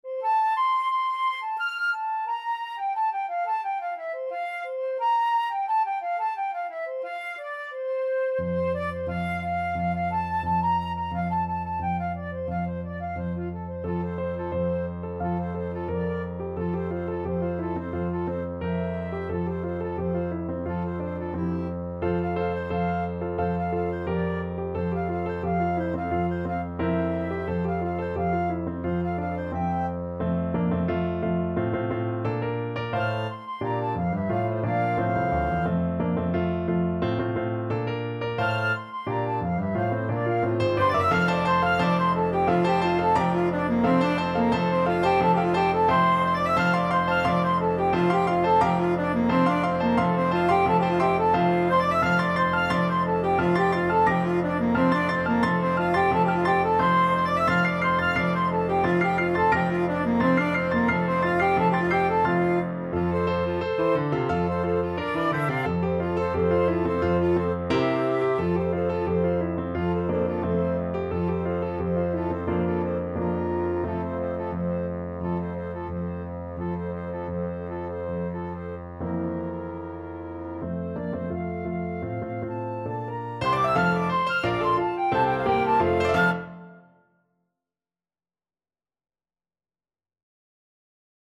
Flute
Clarinet
Bassoon
2/2 (View more 2/2 Music)
Allegro Energico = c.88 (View more music marked Allegro)